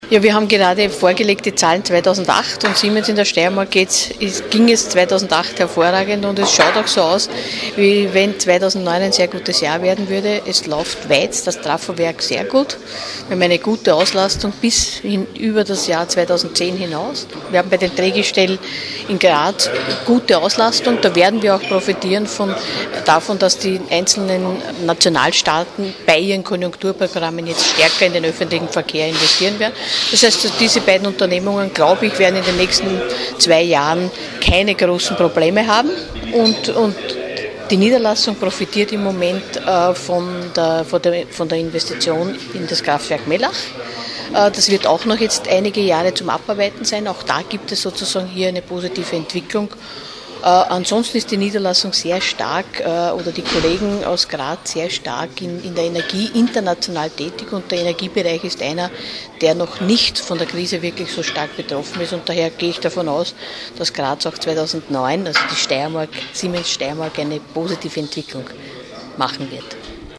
Graz [06.05.2009].- Im Rahmen einer Pressekonferenz im Medienzentrum Steiermark präsentierte die Siemens AG Österreich die Zahlen für das abgelaufene Geschäftsjahr.
Öffnet ein neues Fenster: OT Brigitte Ederer
Frage an die Vorsitzende des Vorstandes der Siemens AG Österreich, Brigitte Ederer: